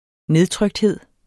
Udtale [ ˈneðˌtʁœgdˌheðˀ ]